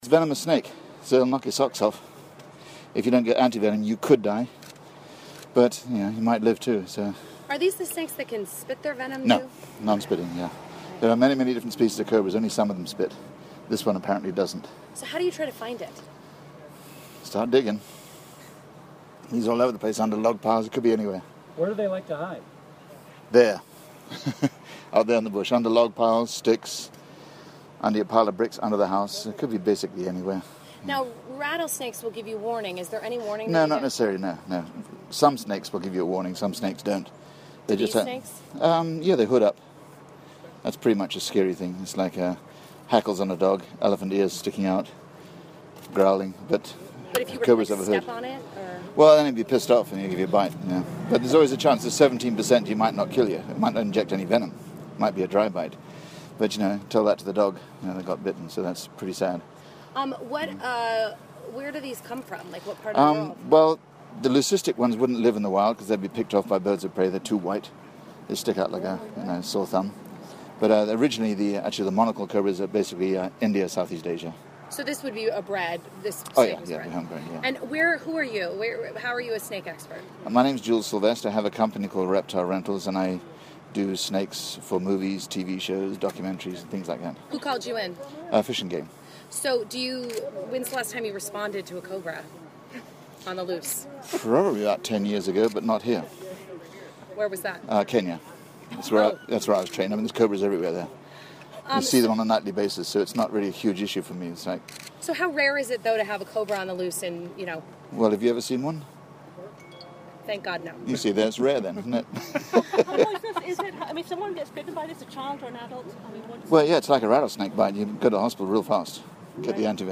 Talks Cobra Escape